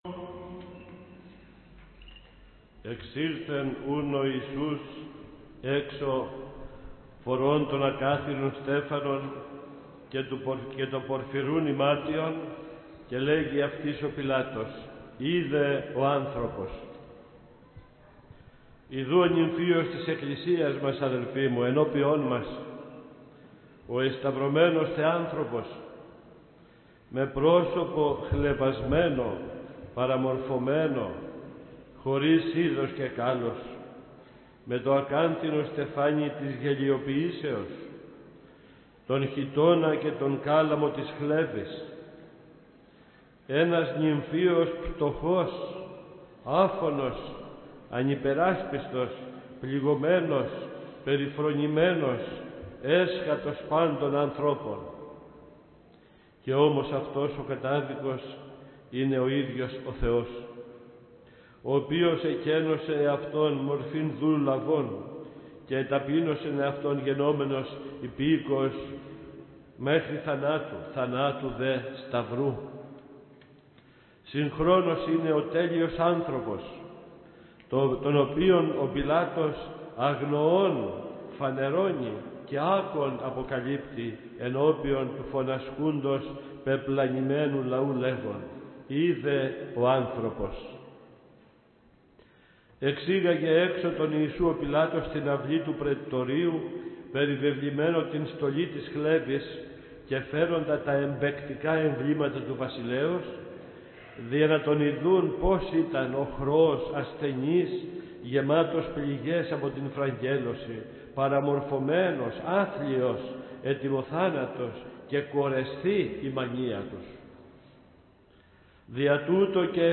omilia_m._paraskeyis.mp3